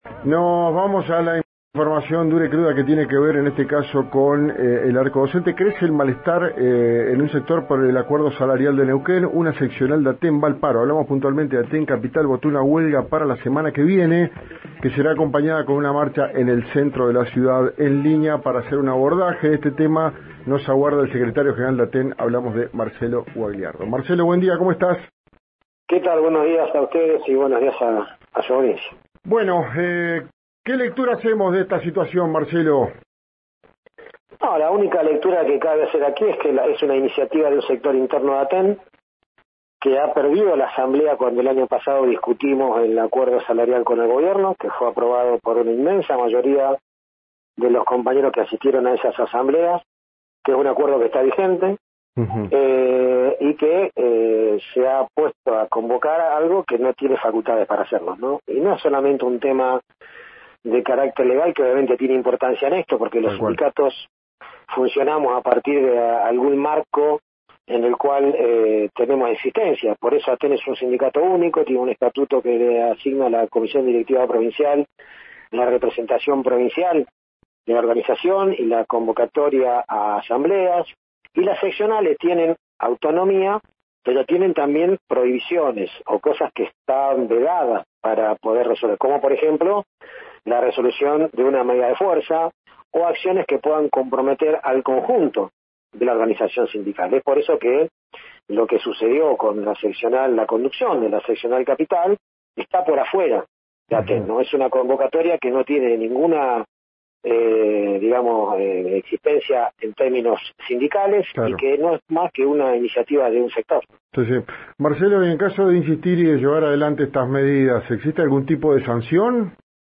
En diálogo con RÍO NEGRO RADIO